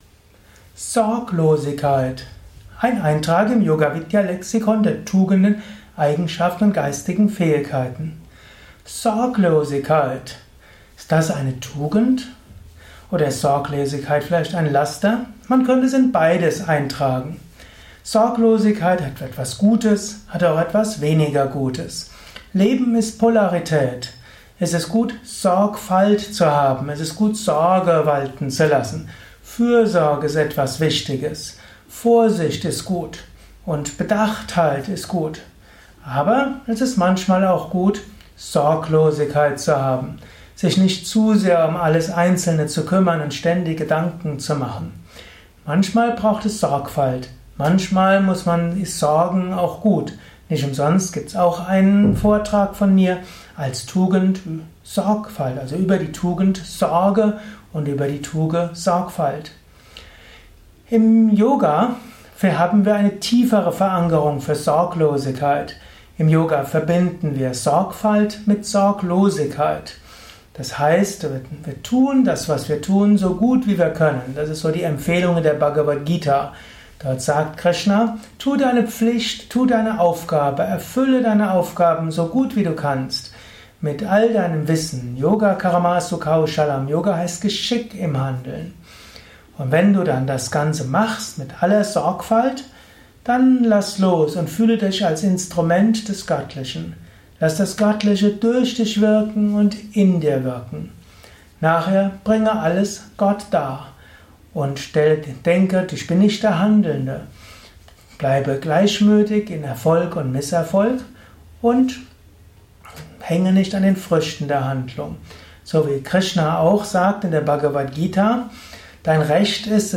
Lausche einem Vortrag über Sorglosigkeit, eine Tugend, eine Eigenschaft, eine geistige Fähigkeit.
Dies ist die Tonspur eines Videos zur geistigen Eigenschaft Sorglosigkeit.